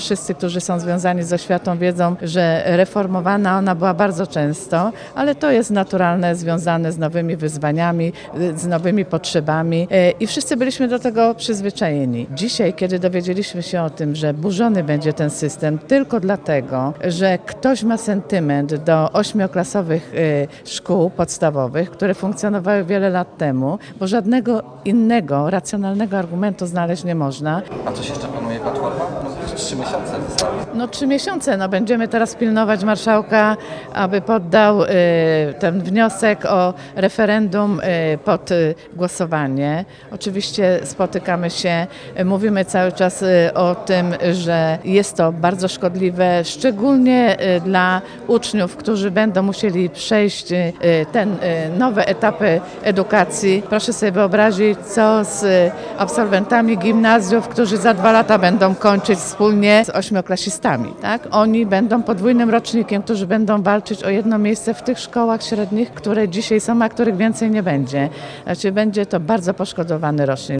-Będziemy walczyć, by ta „deforma”  nie weszła w życie, mówiła Radiu 5 posłanka Ewa Drozd, która jest pedagogiem z 30 letnim stażem.